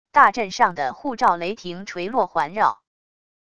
大阵上的护罩雷霆垂落环绕wav音频